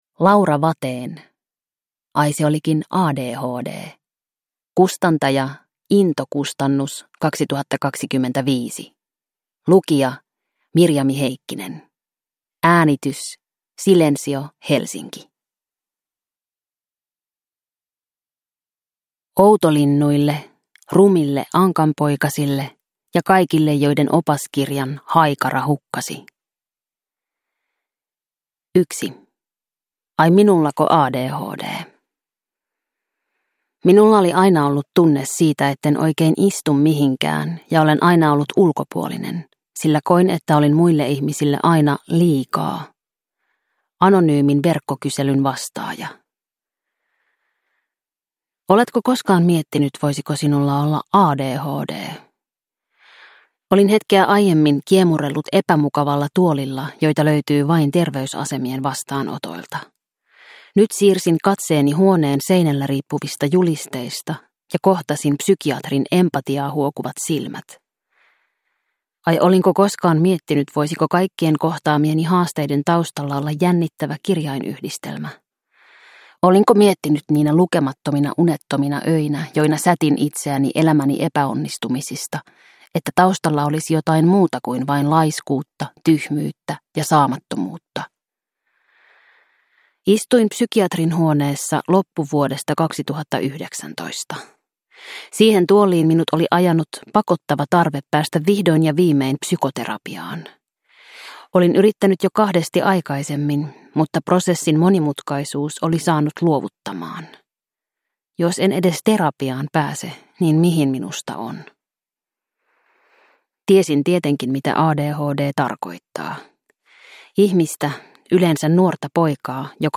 Ai se olikin ADHD – Ljudbok
• Ljudbok